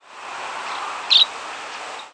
Tree Swallow diurnal flight calls
Fig.3. Colorado July 16, 2001 (MO).
"Chi-deep" call from bird in flight.